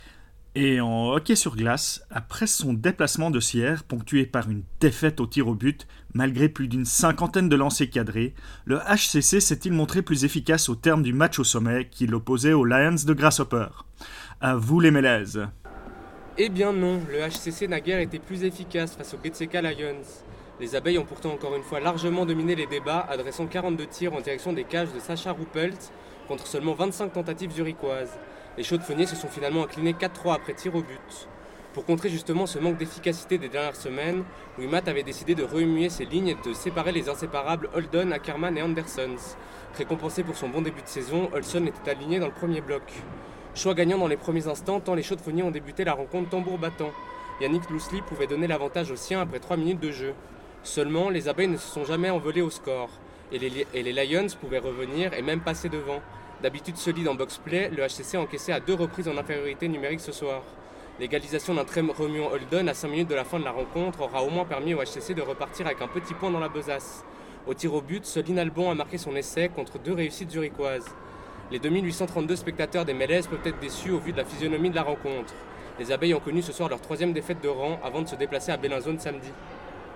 qui avait pour cadre la Patinoire des Mélèzes de La Chaux-de-Fonds et la rencontre entre le HCC et les GSK Lions.